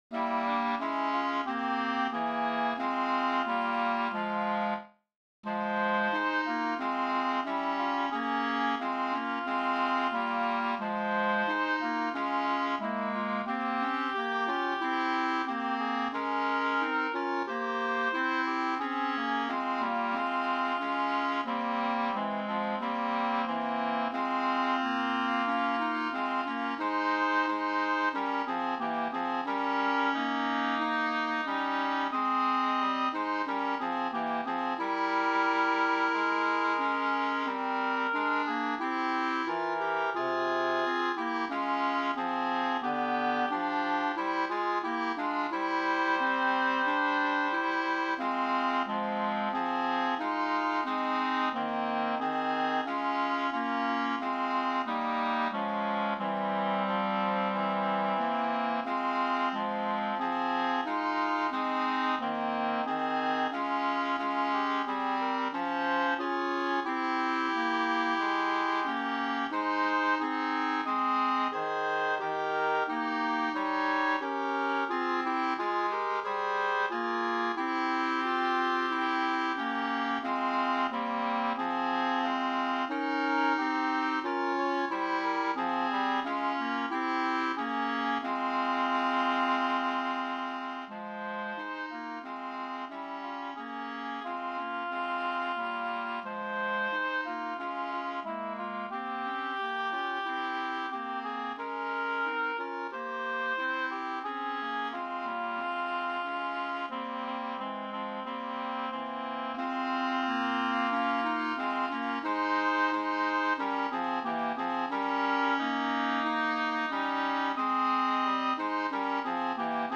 Chorale Diasporim Zinger de Grenoble : musiques populaires et traditionnelles du peuple juif : ashkenaze (yiddish), sefarade (judeo-espagnol), oeuvres de compositeurs de la Diaspora, de la Renaissance a nos jours
Vilne-3voix-tutti.mp3